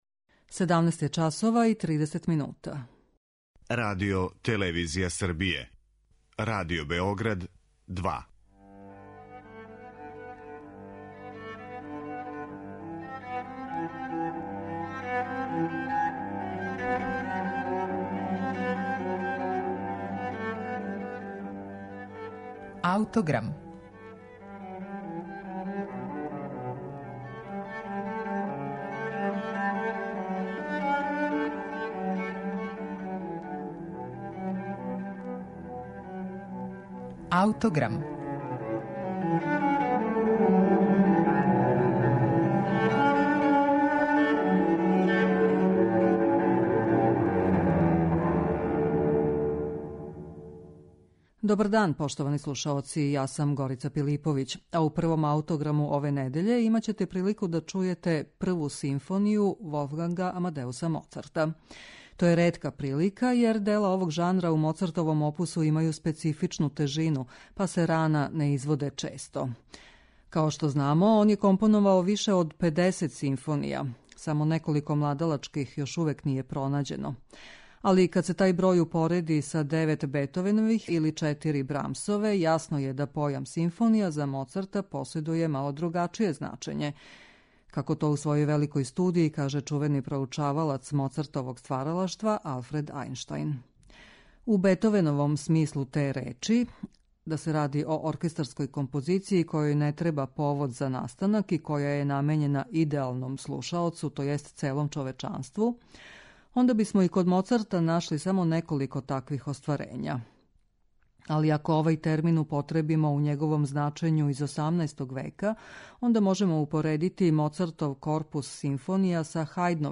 тј. емитоваће његово прво дело овог жанра, које је компоновао кад је имао само девет година.